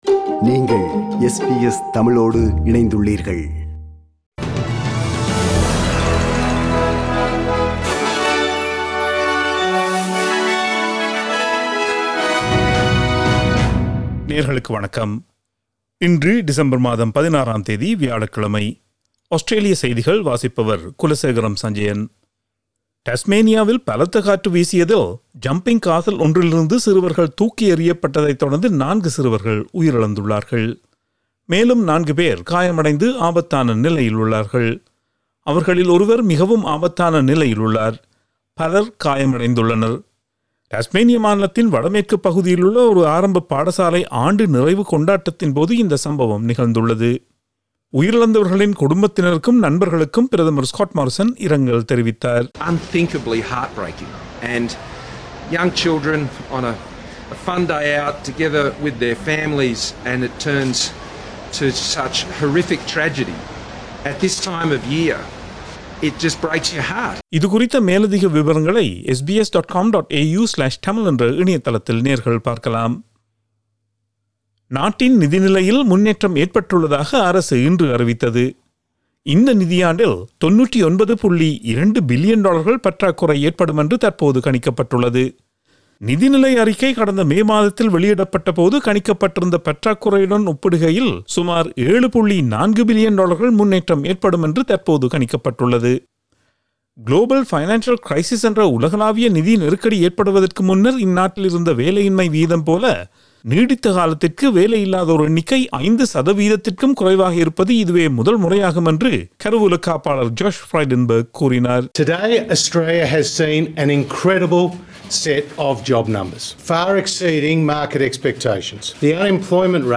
Australian news bulletin for Thursday 16 December 2021.